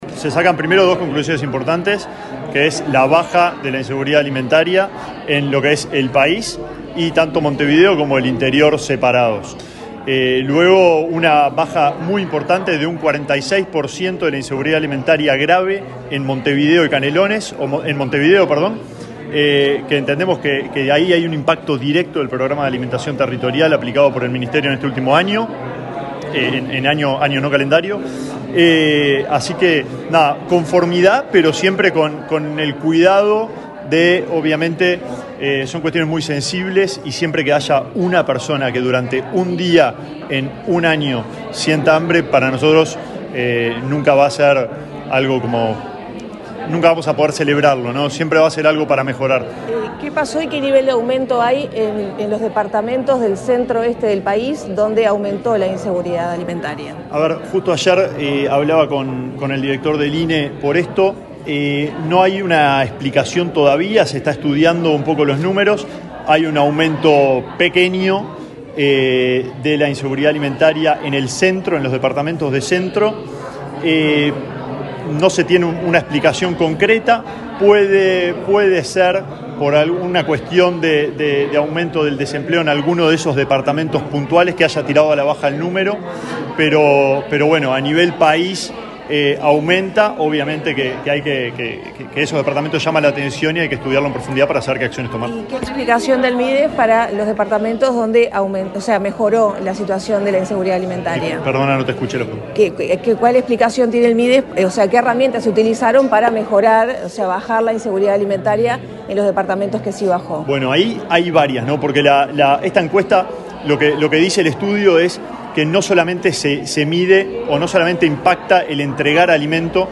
Declaraciones del ministro de Desarrollo Social, Alejandro Sciarra
Este miércoles 13 en la sede del Ministerio de Desarrollo Social, el ministro Alejandro Sciarra, dialogó con la prensa, luego de participar de la